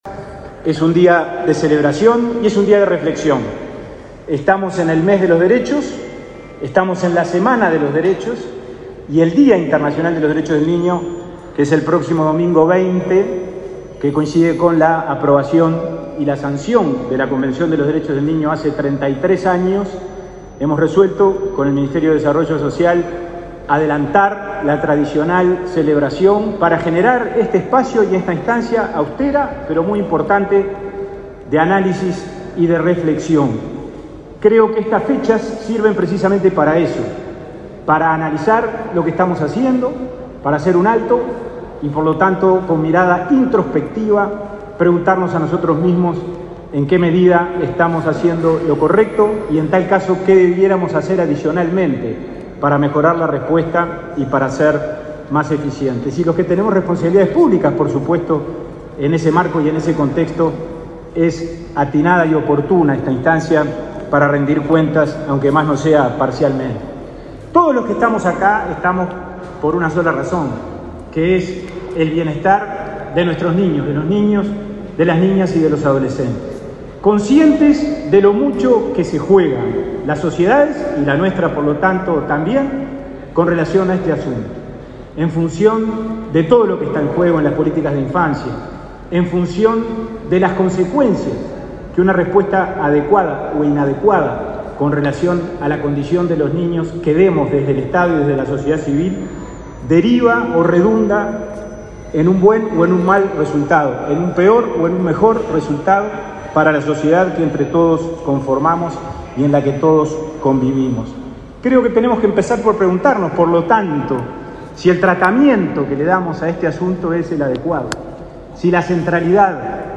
Palabras del presidente del INAU y del ministro Martín Lema
El presidente del INAU y el ministro de Desarrollo Social, Martín Lema, participaron en la conferencia por el 33.° aniversario de aprobada la